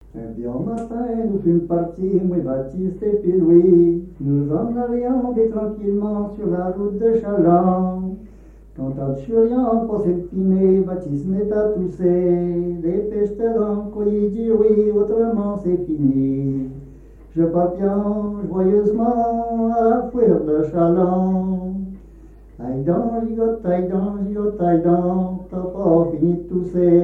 Mémoires et Patrimoines vivants - RaddO est une base de données d'archives iconographiques et sonores.
Musique à danser, rondes chantées et monologue
Pièce musicale inédite